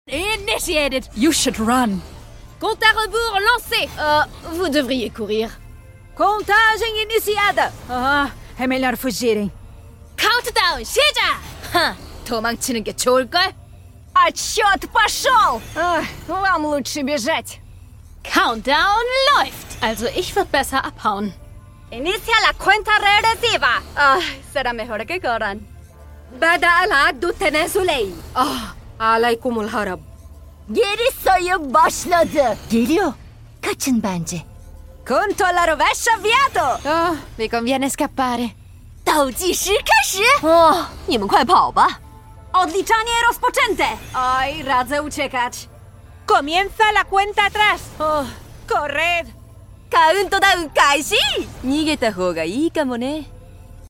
All Killjoy Ultimate Voice Lines sound effects free download